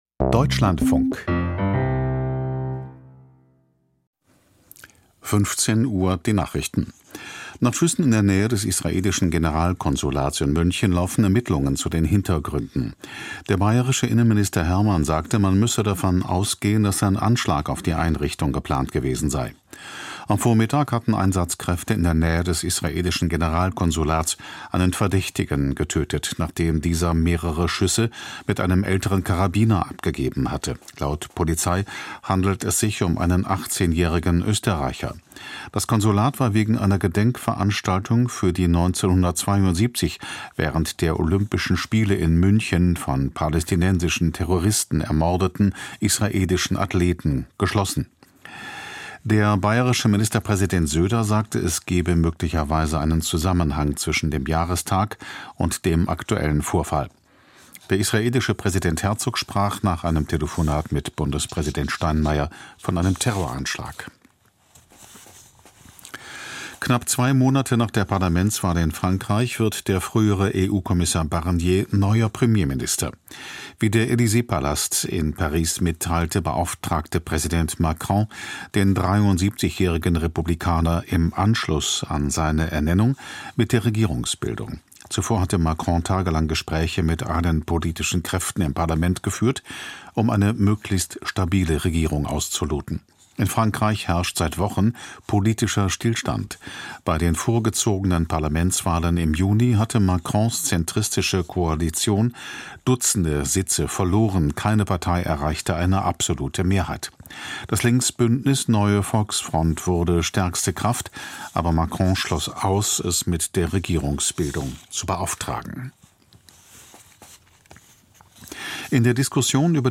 Sich "richtig" für einen Bildungsweg entscheiden: Interview